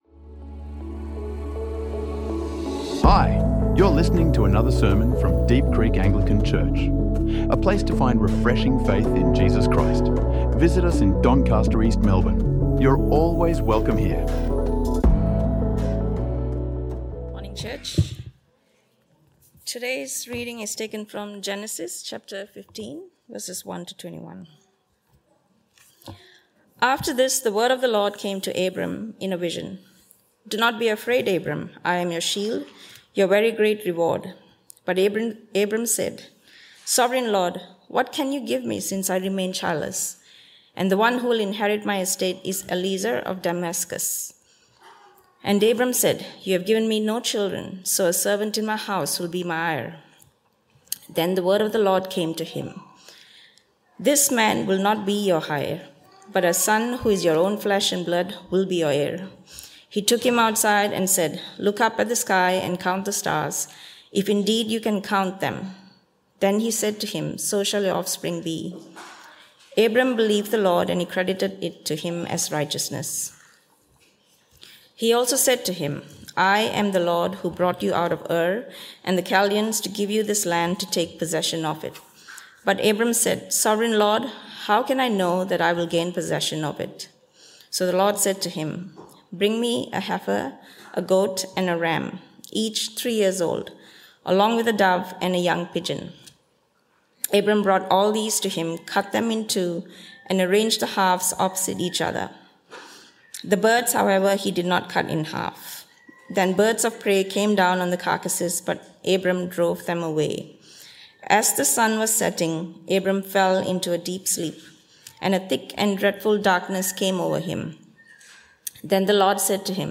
Sermon on Genesis 15: God reassures Abraham with a covenant.